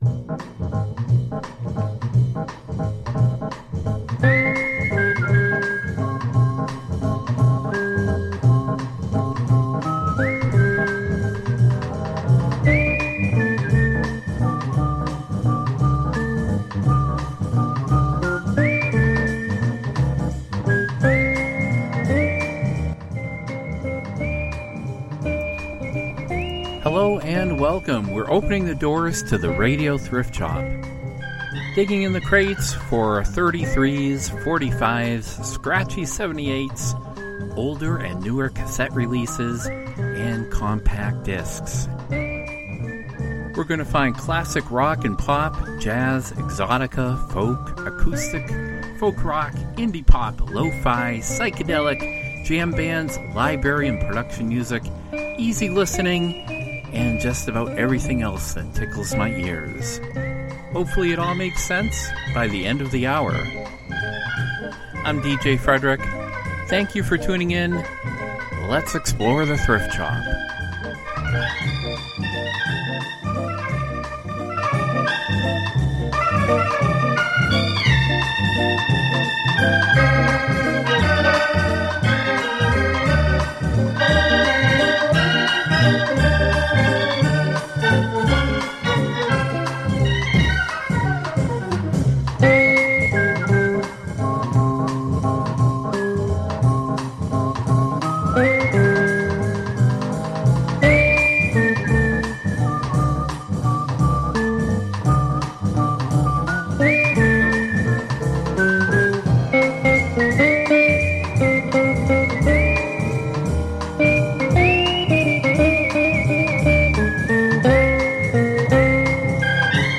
Program Type: Music Speakers